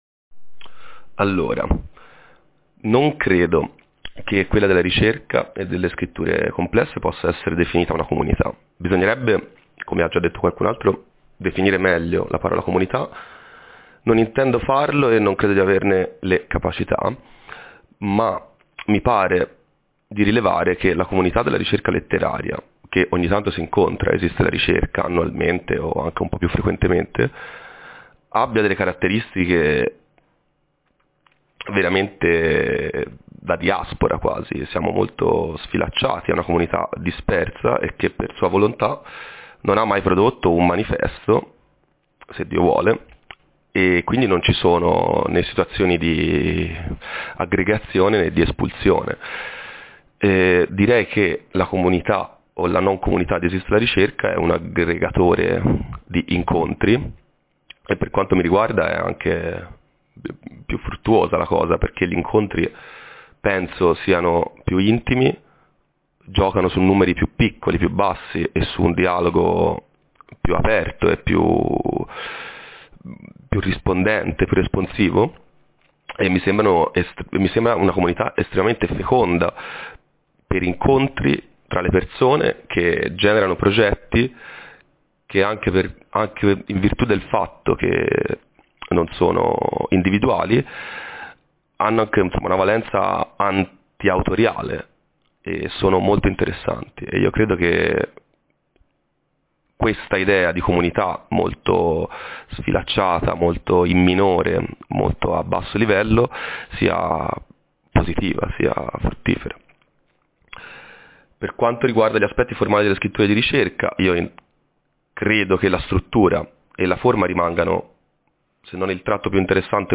È lasciata la massima libertà nel rispondere (o non rispondere) via mp3, ma è richiesta una serie di condizioni in linea con la natura estemporanea di ELR: i vocali non devono essere ‘preparati’, né letti, né (poi) editati, né in sostanza pensati come elementi di un’esposizione calligrafica / accademica, bensì pronunciati al microfono e registrati così come nascono.